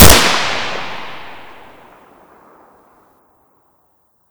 8abddf23c7 Divergent / mods / Boomsticks and Sharpsticks / gamedata / sounds / eft_wp / aks74u / shoot.ogg 47 KiB (Stored with Git LFS) Raw History
shoot.ogg